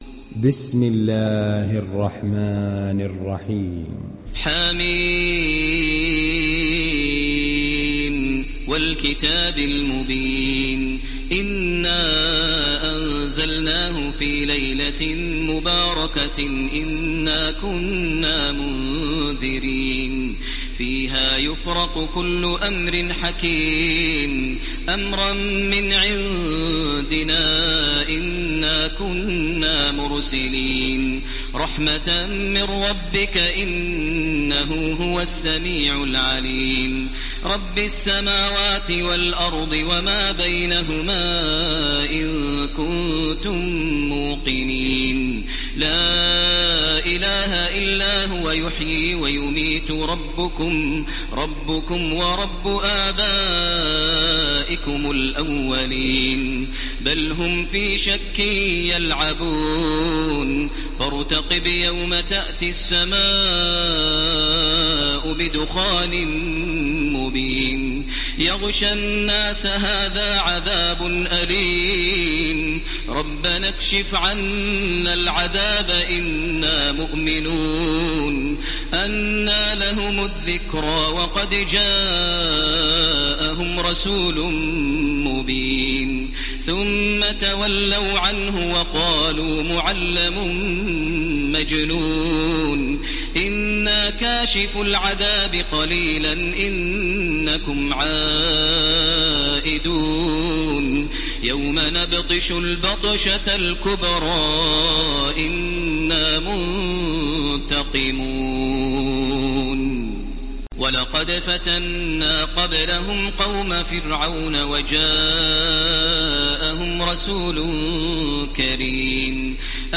Download Surat Ad Dukhan Taraweeh Makkah 1430